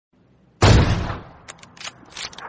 Dzwonek - Strzał i przeładowanie
Dźwięk strzału z broni i przeładowanie.
strzal-luska.mp3